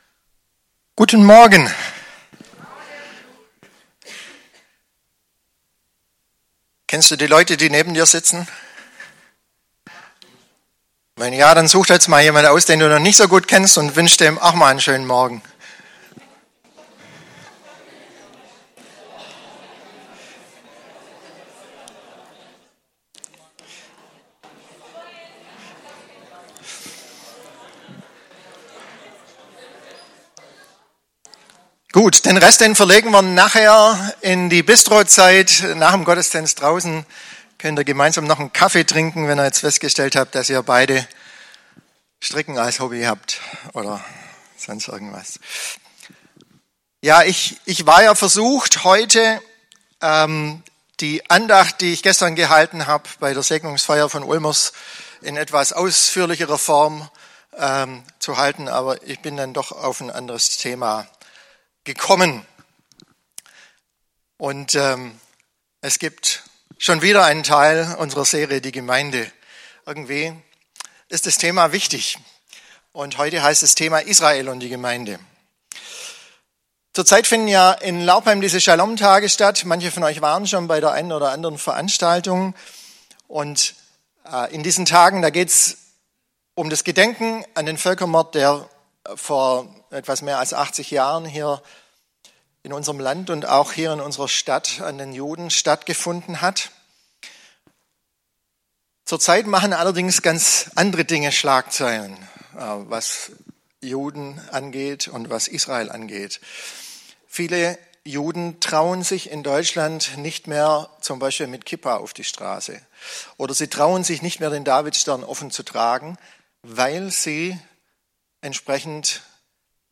Predigt-Details - FCG Ecclesia Laupheim